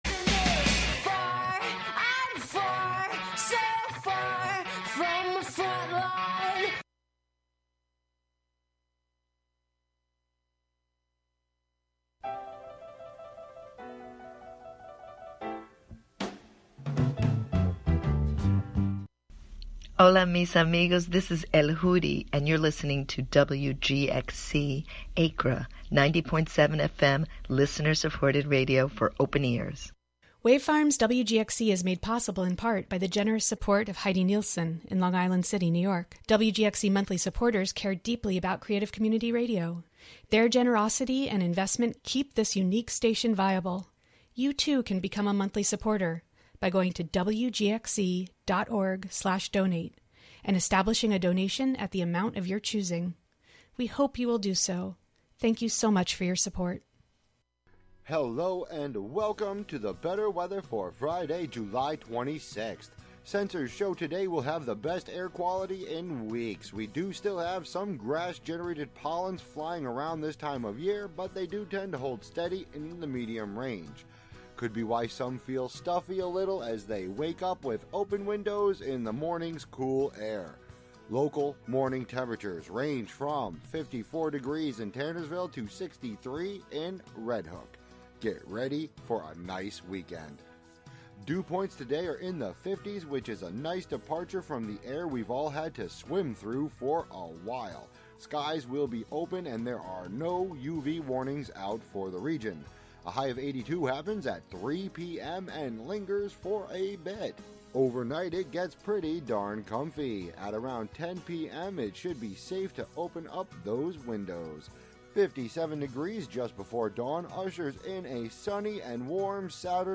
5pm Today we are presenting Latinx Blues.